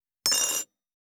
225,机に物を置く,テーブル等に物を置く,食器,グラス,コップ,工具,小物,雑貨,コトン,トン,ゴト,ポン,ガシャン,ドスン,ストン,カチ,タン,
コップ効果音物を置く